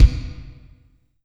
Bass-Drum-1.aif